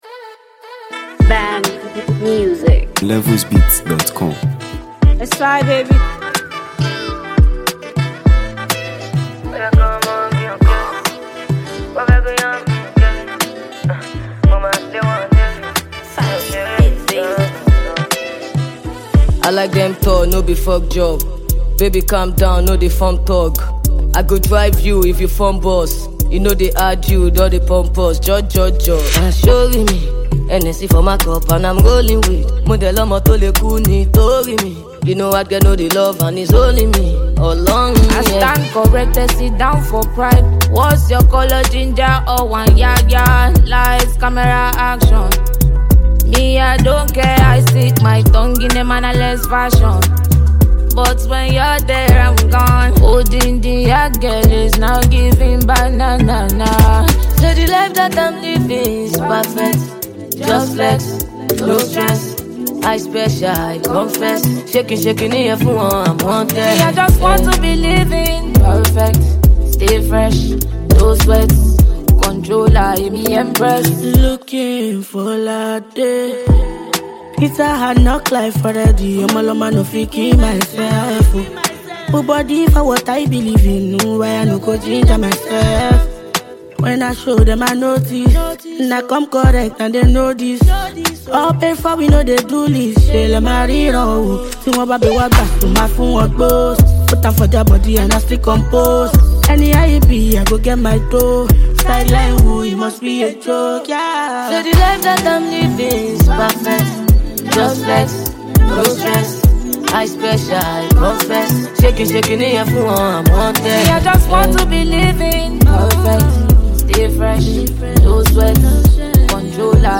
Nigeria Music 2025 2:51